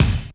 HR16B   BD 3.wav